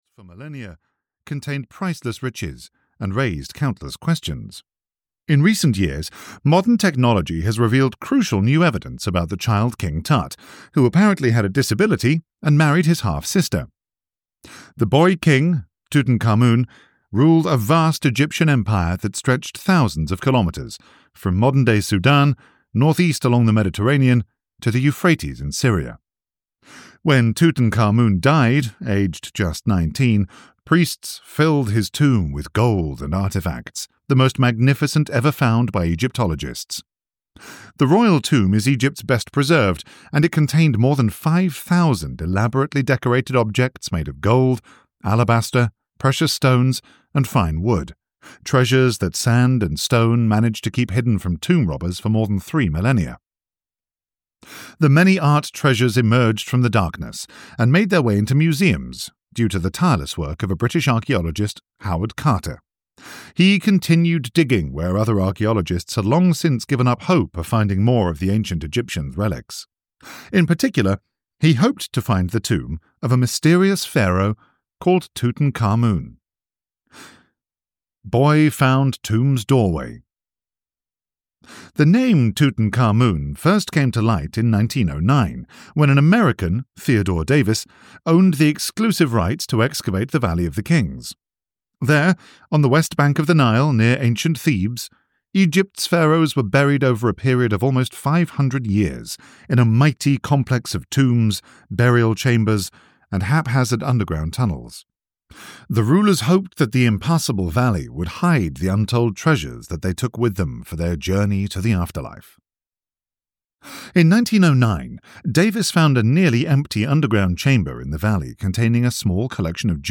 The Greatest Mysteries of History (EN) audiokniha
Ukázka z knihy